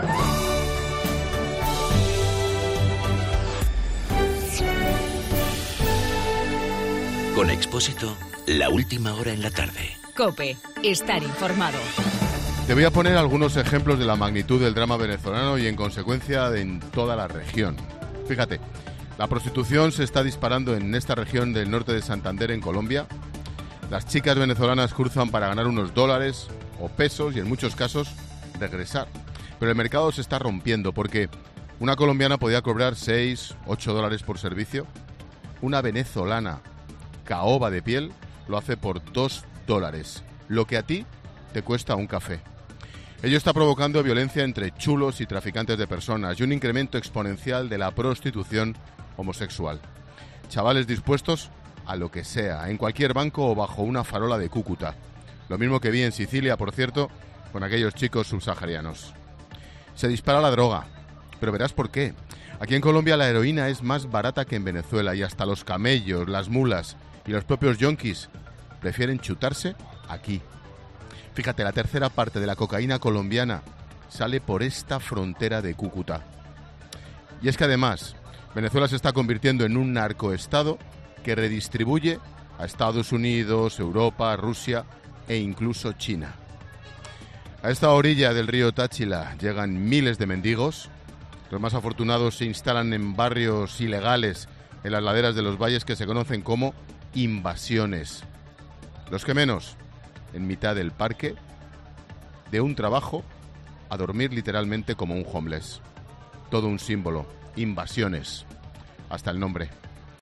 Monólogo de Expósito
Ángel Expósito analiza la crisis de Venezuela, desde su frontera con Colombia.